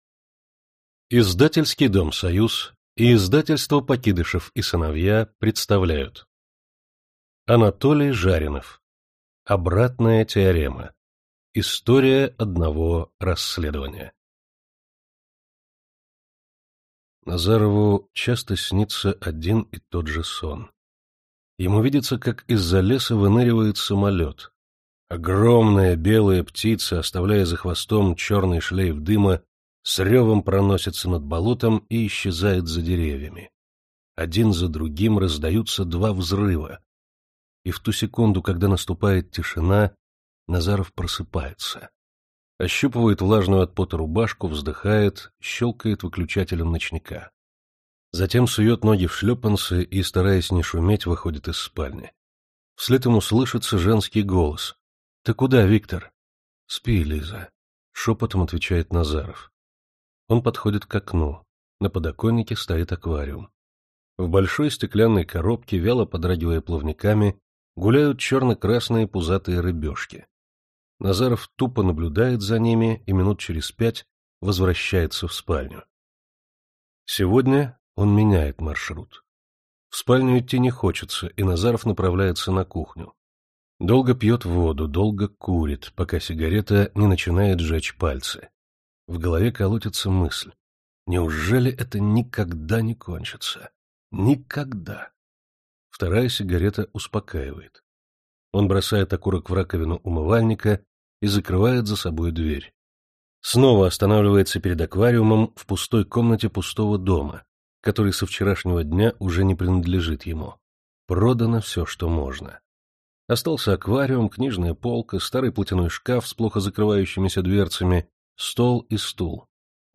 Аудиокнига Обратная теорема | Библиотека аудиокниг